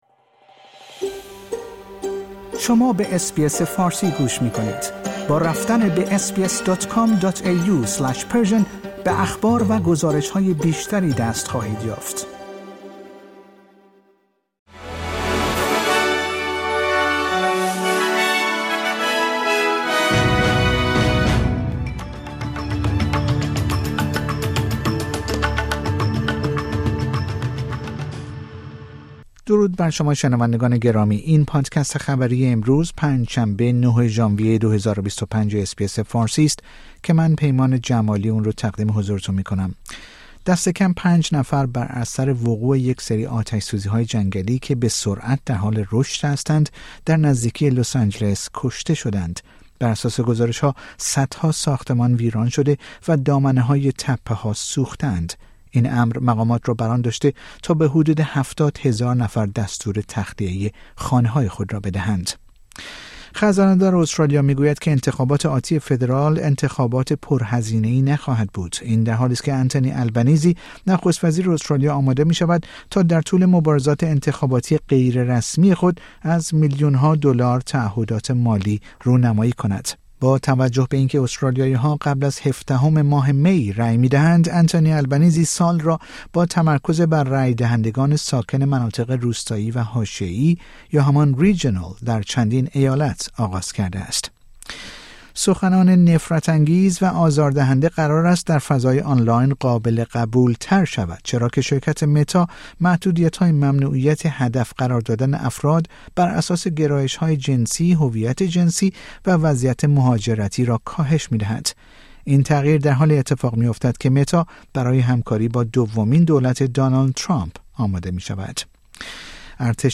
در این پادکست خبری مهمترین اخبار استرالیا در روز پنج شنبه ۹ ژانویه ۲۰۲۵ ارائه شده است.